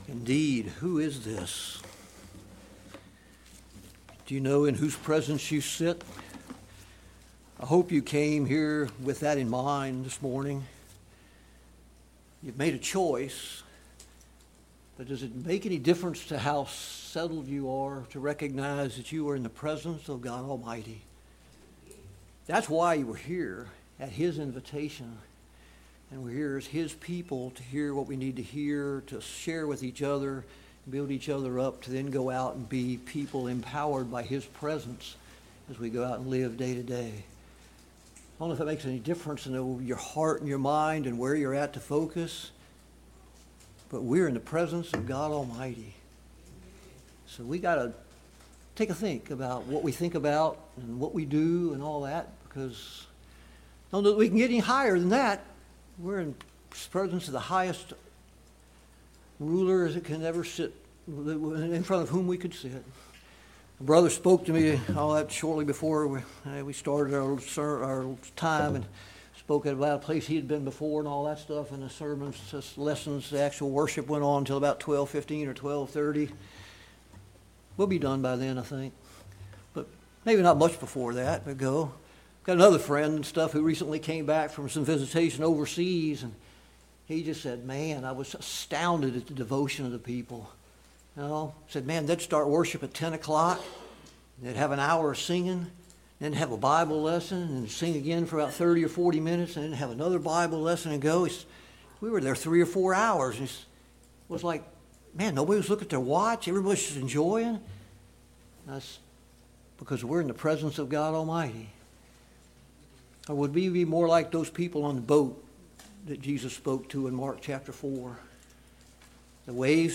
Passage: Psalms 46, Mark 4:35-41 Service Type: AM Worship « The Power of Prayer Study of Paul’s Minor Epistles